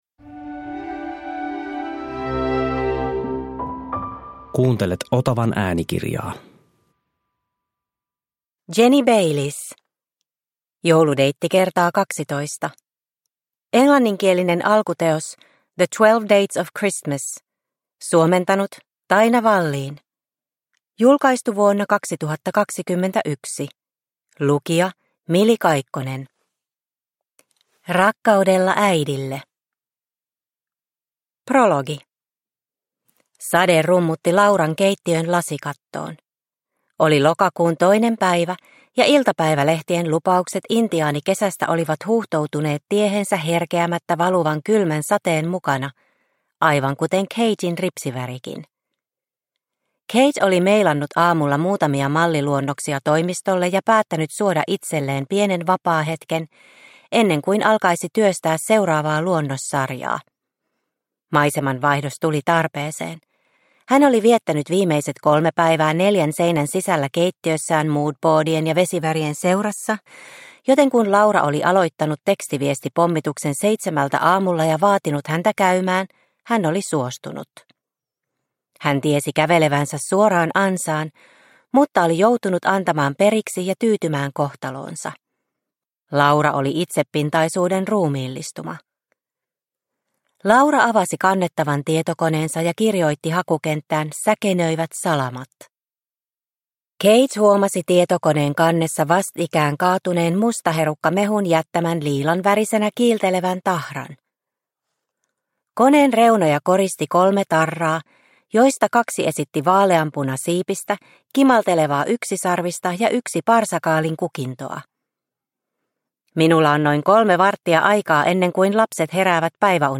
Jouludeitti x 12 – Ljudbok – Laddas ner